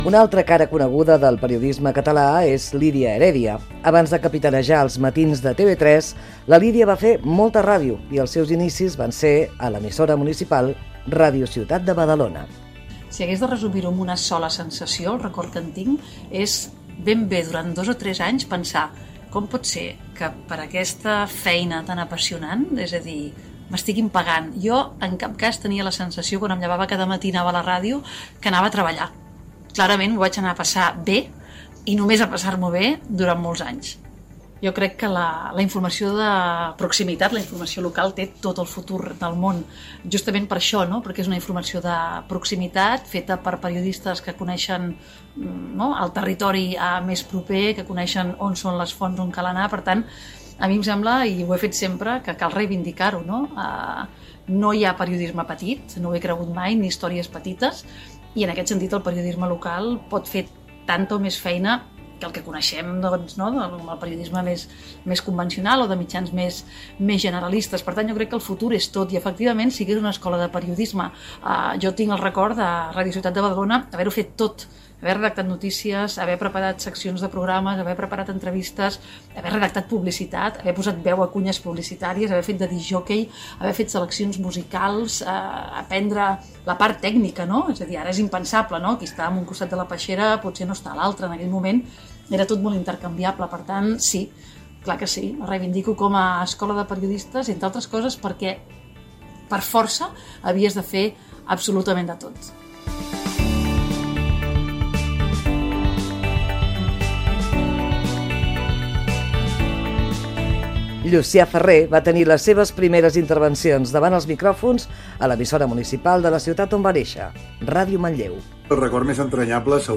Especial emès amb motiu del Dia Mundial de la Ràdio 2020.
Divulgació